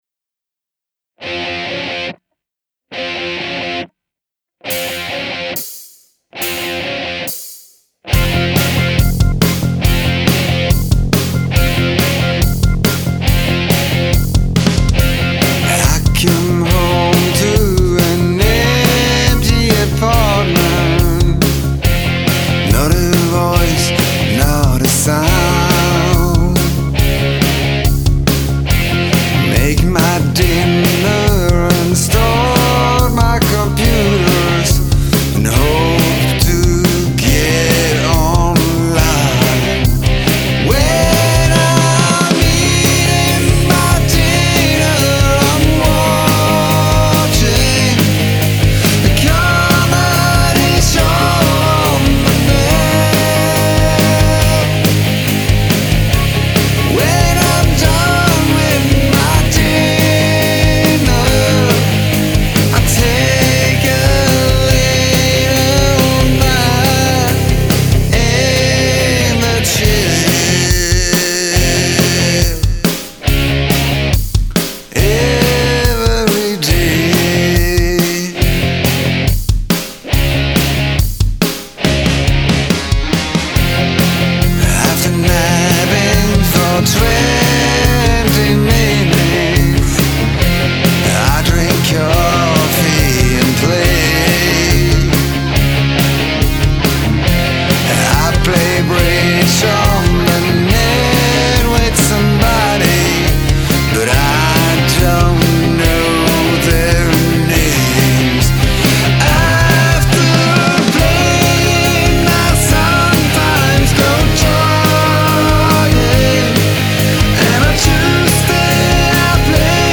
Mix a rock song with blues theme...?
The mix is completely static and would also benefit from automation.
I keep hearing a vocal harmony in my head, so I just created one with Melodyne . Needed a bit more snare too.